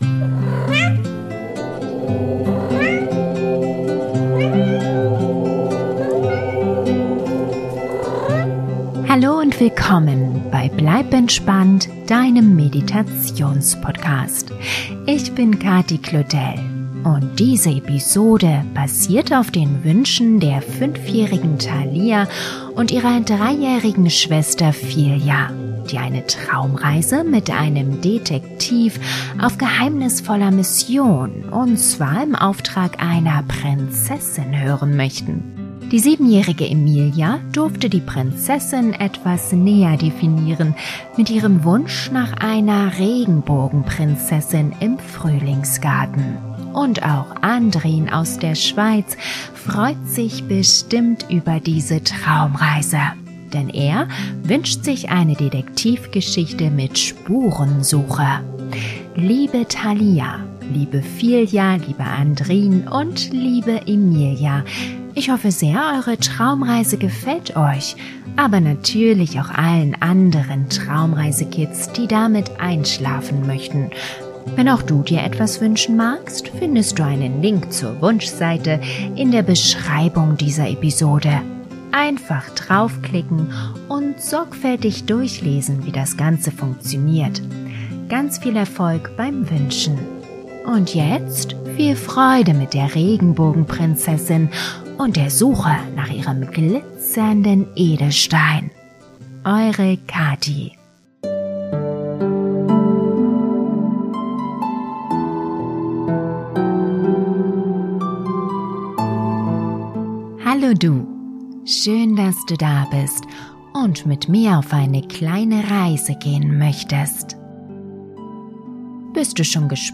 Beruhigende Naturgeräusche wie das Summen der Bienen, das Zwitschern der Vögel und das Plätschern von Springbrunnen begleiten die Erlebnisse.